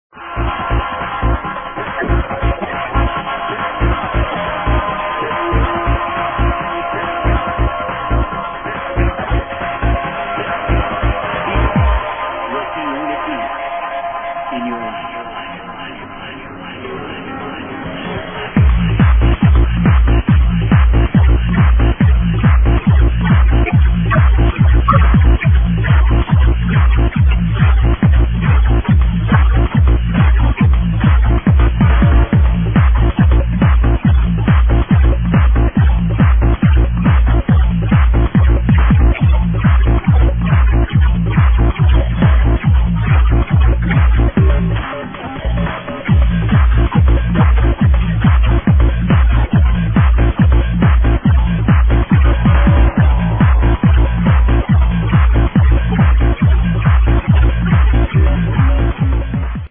Hello! unknown house tune - please help !!